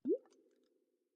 Minecraft Version Minecraft Version latest Latest Release | Latest Snapshot latest / assets / minecraft / sounds / ambient / underwater / additions / bubbles3.ogg Compare With Compare With Latest Release | Latest Snapshot
bubbles3.ogg